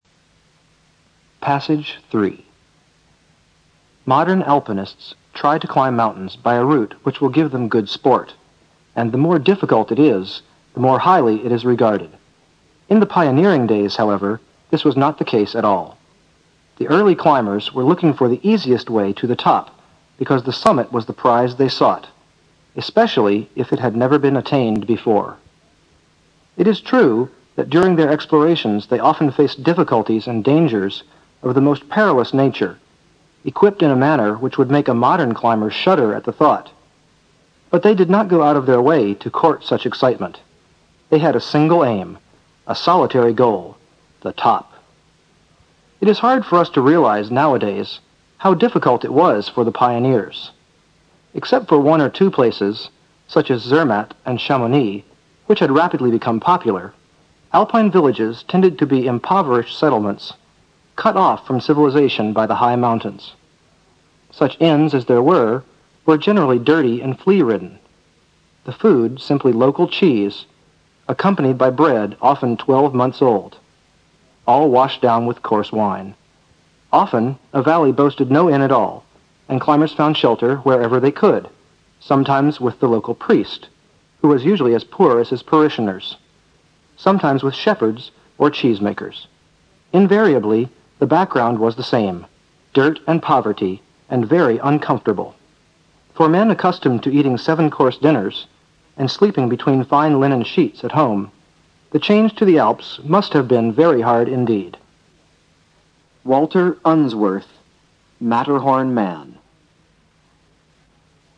新概念英语85年上外美音版第四册 第3课 听力文件下载—在线英语听力室